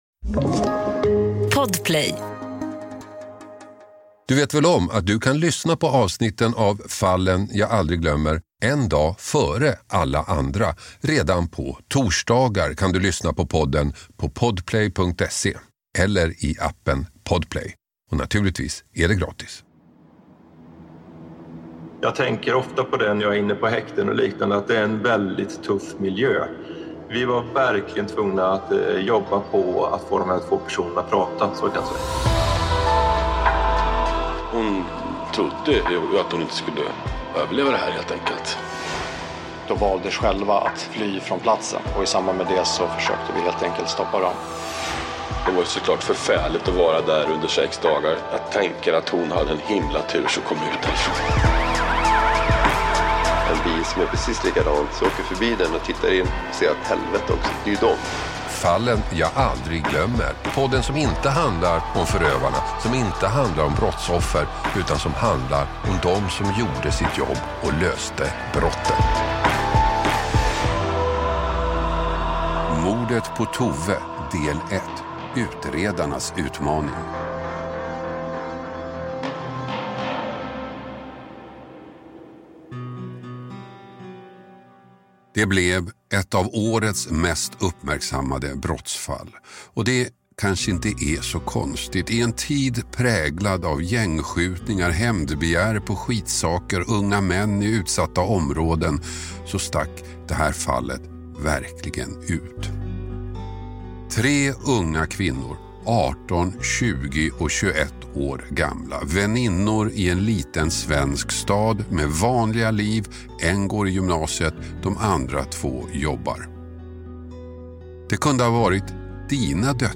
Misstankar riktas tidigt i utredningen mot vännerna, en 20-årig och en 18-årig kvinna. Utredarna förbryllas av de unga kvinnornas tystnad till dess de hittar ett nytt tillvägagångssätt att komma vidare i utredningen. Hasse Aro intervjuar utredaren